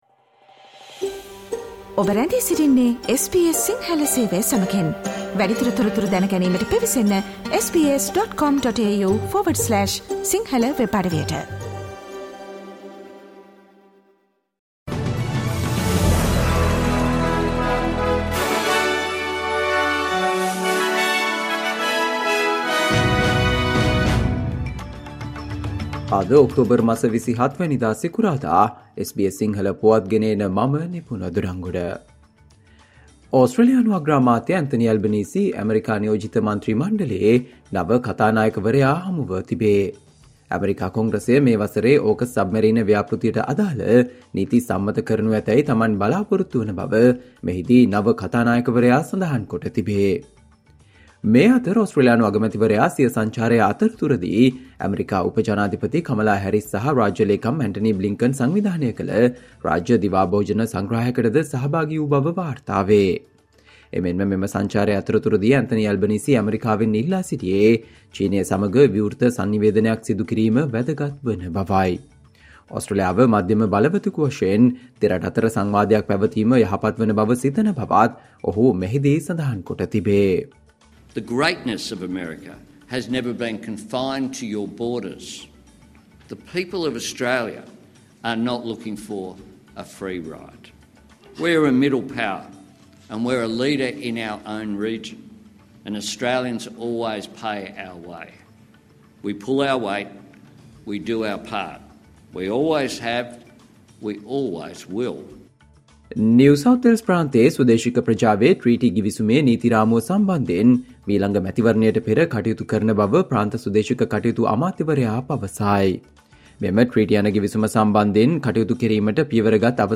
Australia news in Sinhala, foreign and sports news in brief - listen Sinhala Radio News Flash on Friday 27 October 2023.